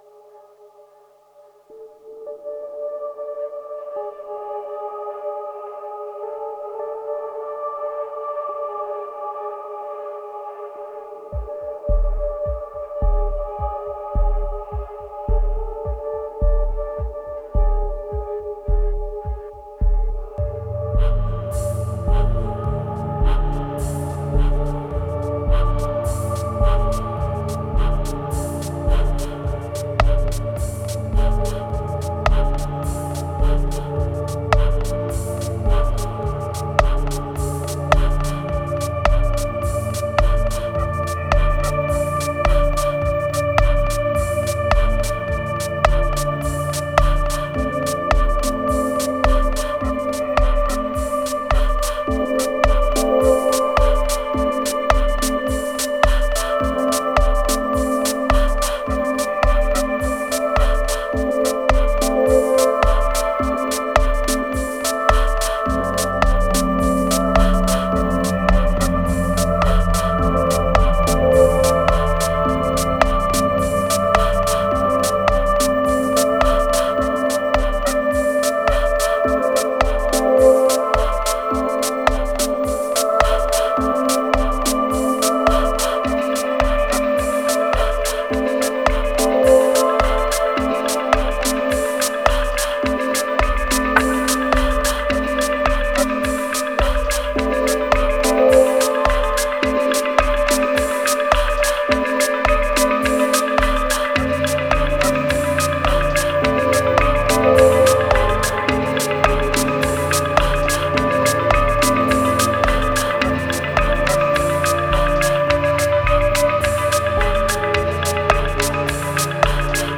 2489📈 - 5%🤔 - 106BPM🔊 - 2016-10-02📅 - -390🌟